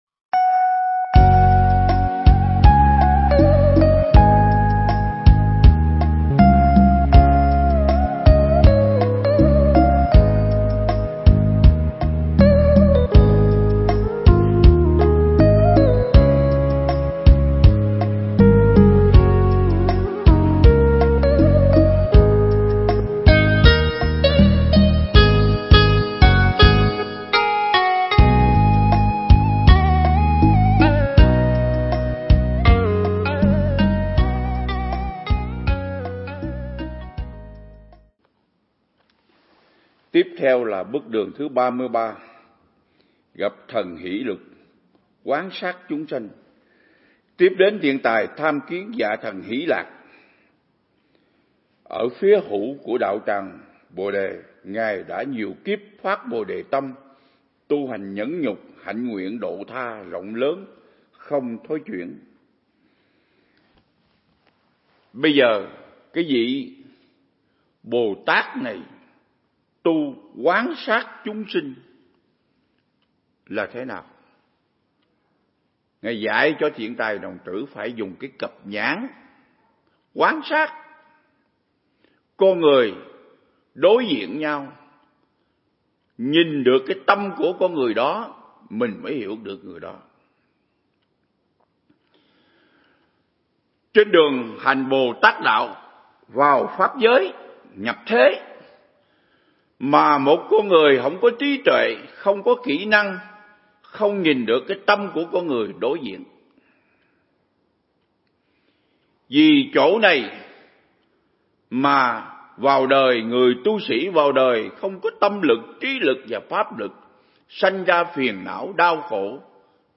Mp3 Thuyết Giảng Ứng Dụng Triết Lý Hoa Nghiêm Phần 56